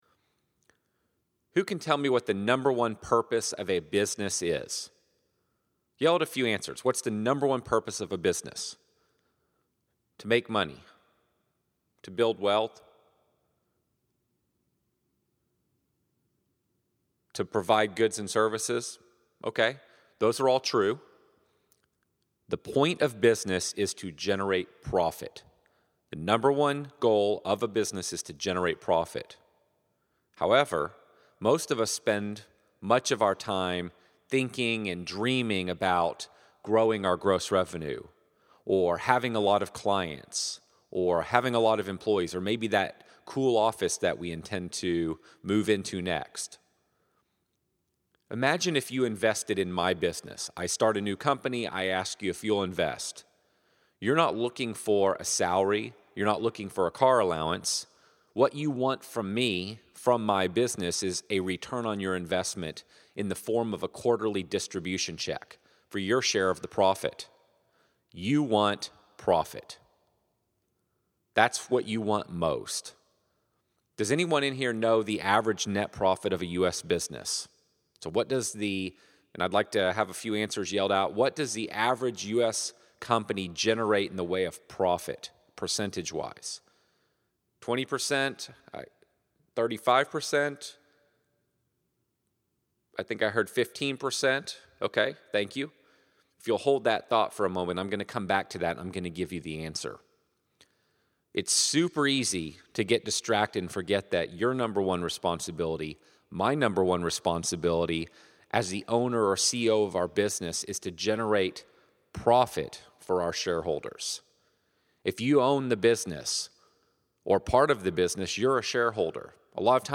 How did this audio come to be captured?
Service Autopilot Conference | Profits Talk